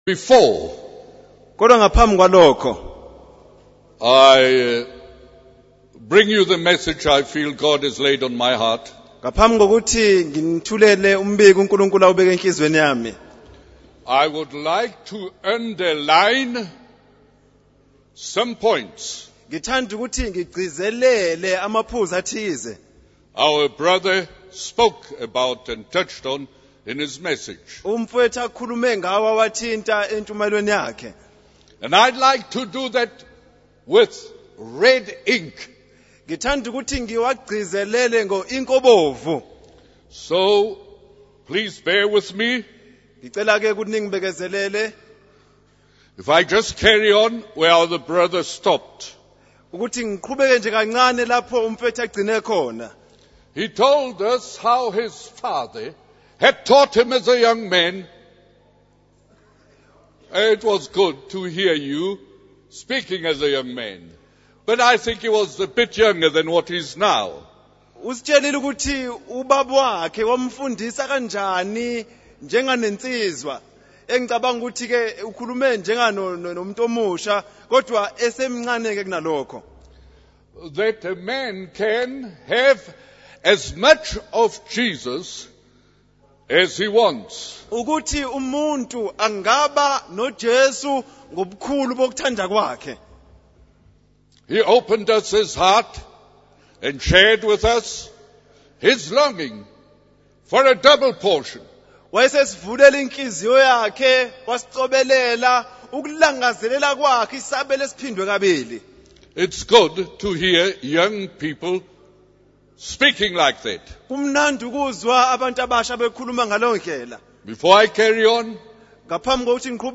In this sermon, the speaker reflects on the message shared by another preacher and highlights key points. He emphasizes the importance of teaching children the ways of the Lord and encourages preachers to have a burning passion for Christ.